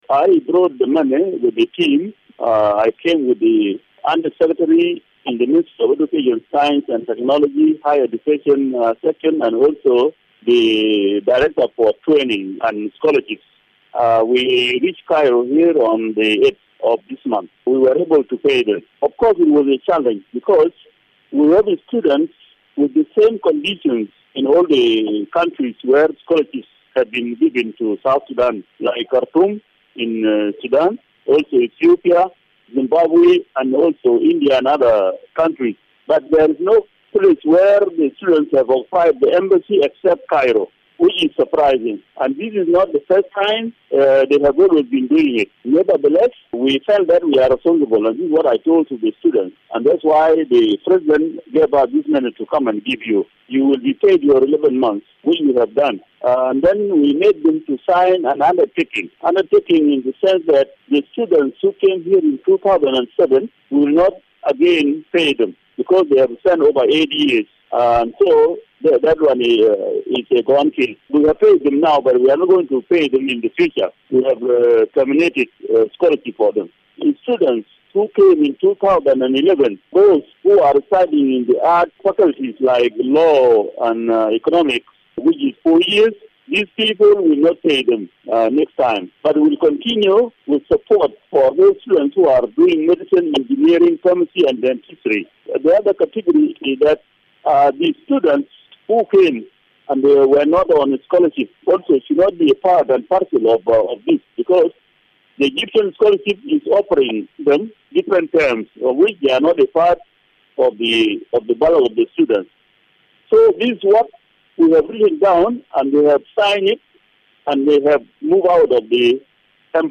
Deputy Education Minister Bol Makueng led the team that traveled to make the payments. He spoke to Radio Miraya reporter